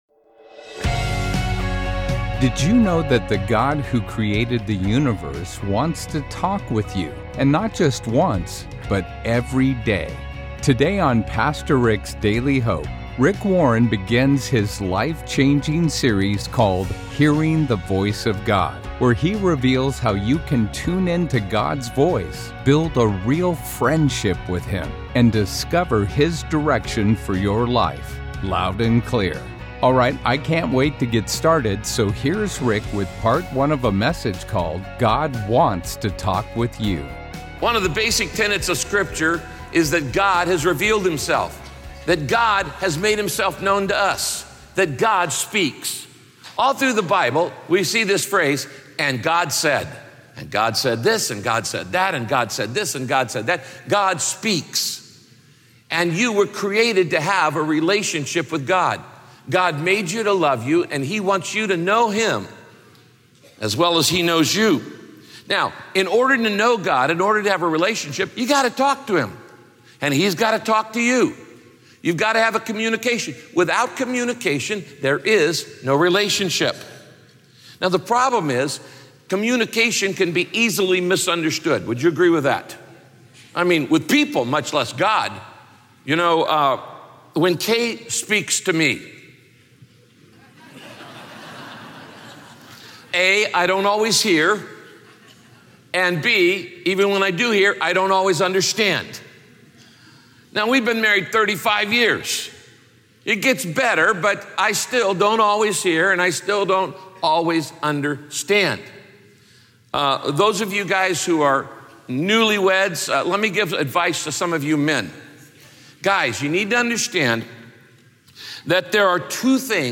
In this teaching, Pastor Rick talks about the barriers that can block your reception and close your mind to what God wants to say.